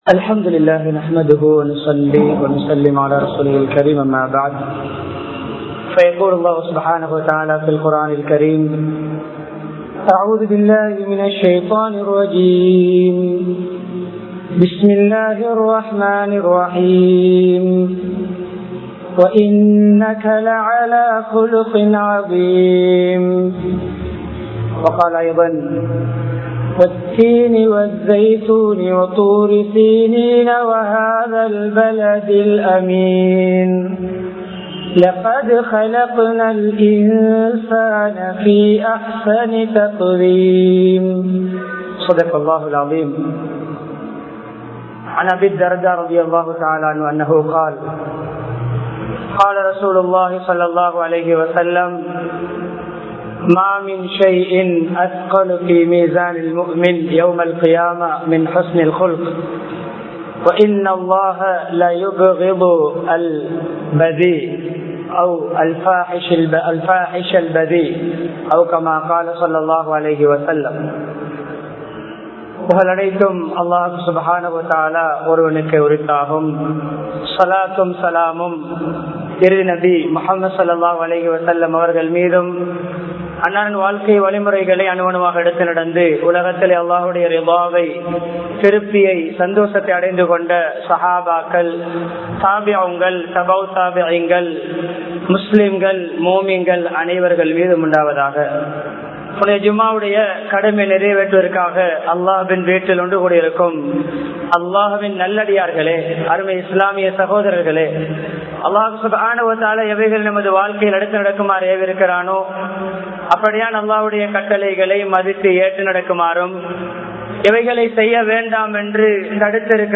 நற்குணத்தின் விளைவுகள் | Audio Bayans | All Ceylon Muslim Youth Community | Addalaichenai
Kurunegala, Mallawapitiya Jumua Masjidh